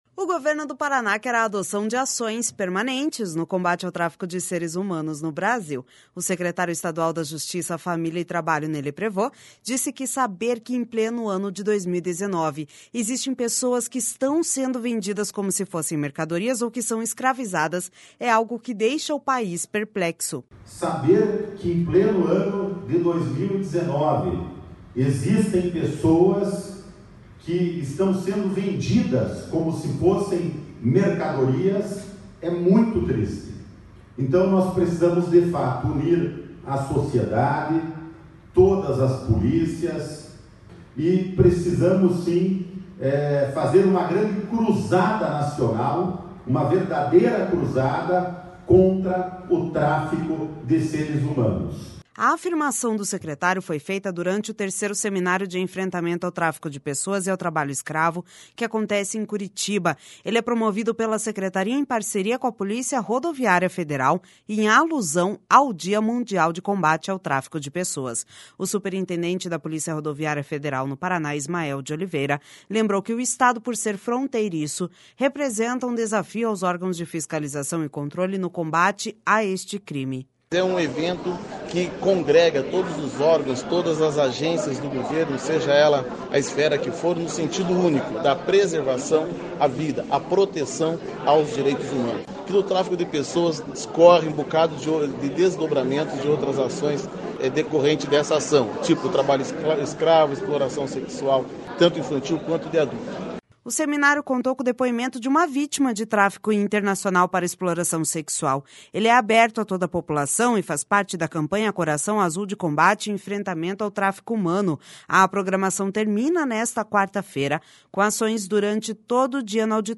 O Governo do Paraná quer a adoção de ações permanentes no combate ao tráfico de seres humanos no Brasil. O secretário estadual da Justiça, Família e Trabalho, Ney Leprevost, disse que saber que em pleno ano de 2019 existem pessoas que estão sendo vendidas como se fossem mercadorias ou que são escravizadas é algo que deixa o país perplexo.// SONORA NEY LEPREVOST//A afirmação do secretário foi feita durante o 3º Seminário de Enfrentamento ao Tráfico de Pessoas e ao Trabalho Escravo, que acontece em Curitiba.